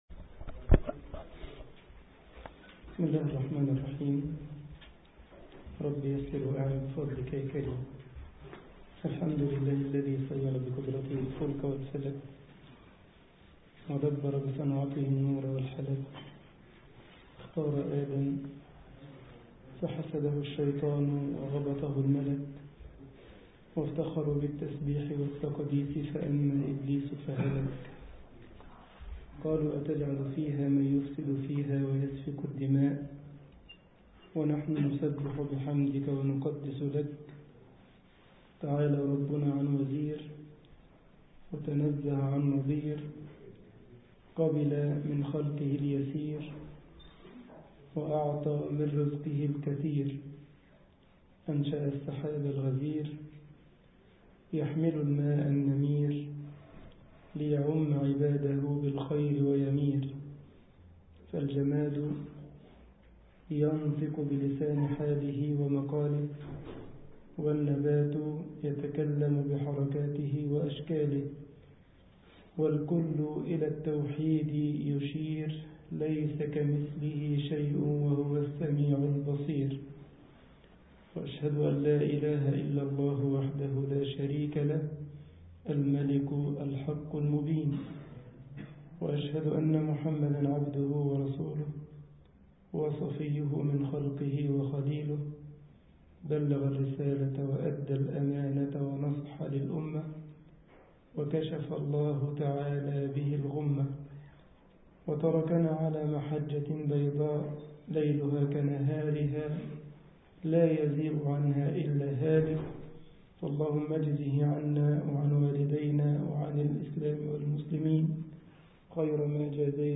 مسجد الجمعية الإسلامية بكايزرسلاوترن ـ ألمانيا